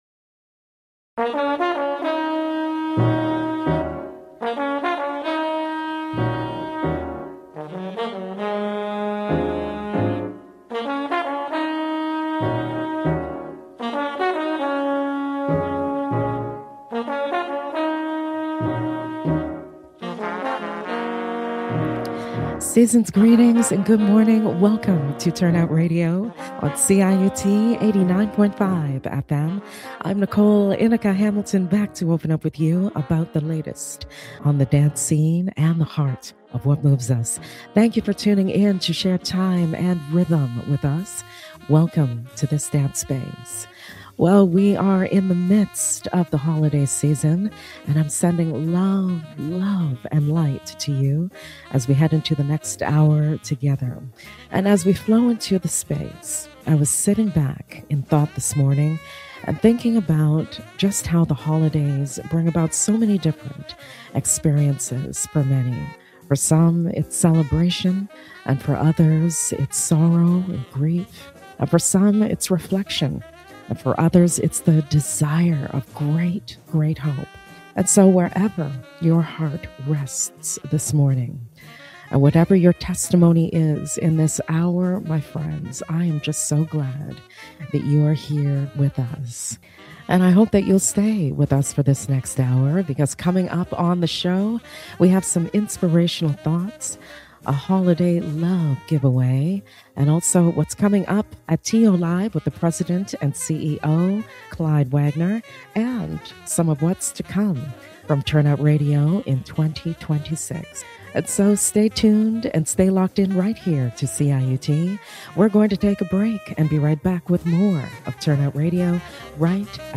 L ive on CIUT 89.5 FM